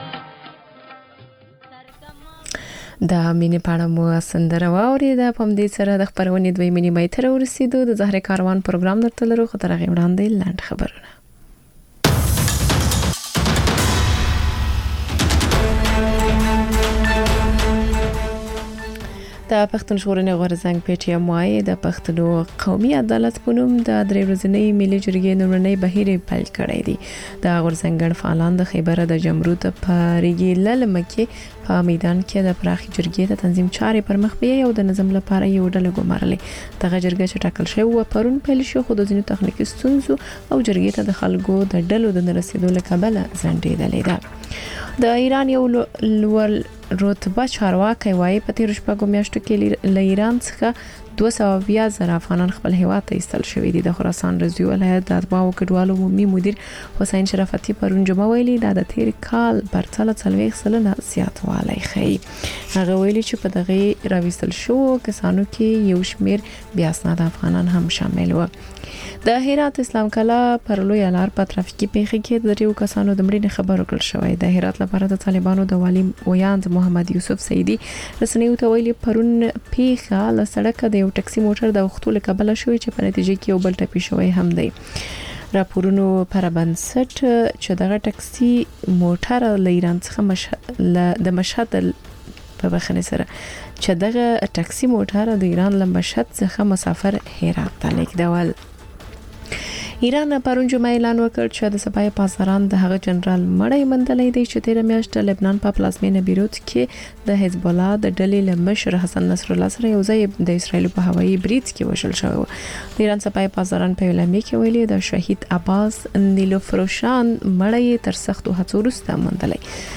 لنډ خبرونه - د زهرو کاروان (تکرار)